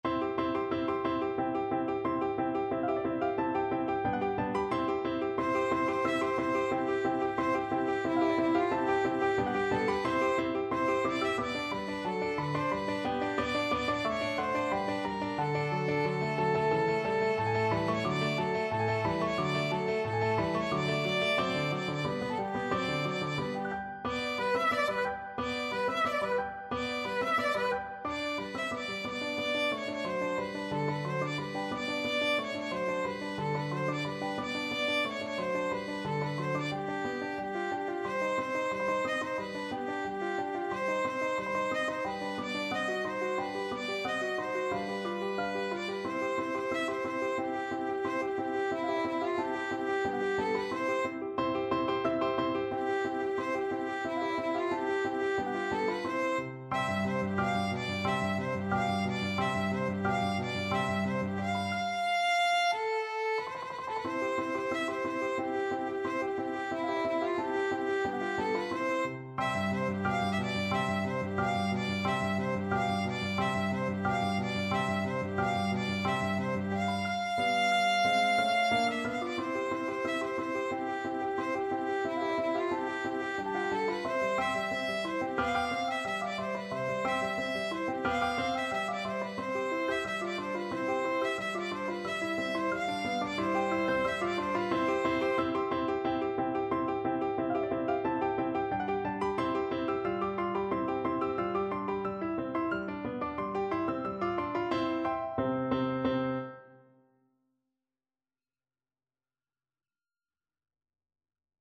C major (Sounding Pitch) (View more C major Music for Violin )
Presto =180 (View more music marked Presto)
2/4 (View more 2/4 Music)
Violin  (View more Intermediate Violin Music)
Classical (View more Classical Violin Music)